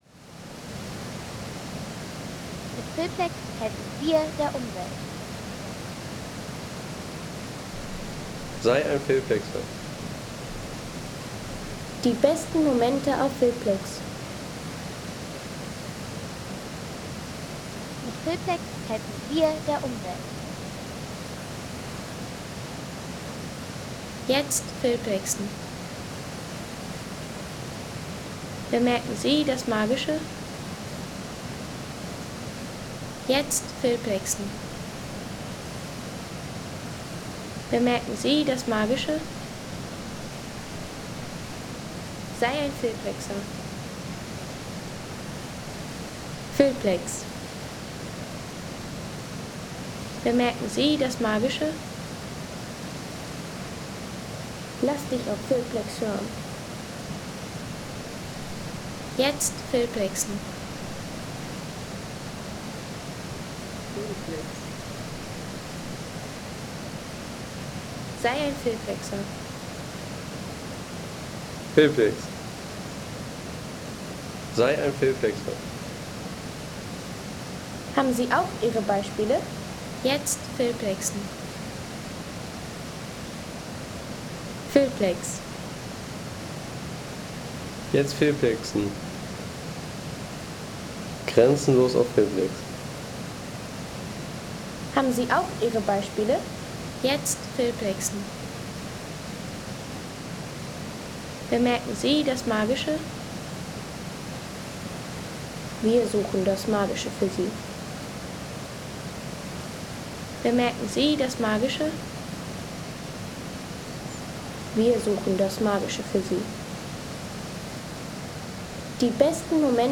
Der Wasserfall - Weißer See
Landschaft - Wasserfälle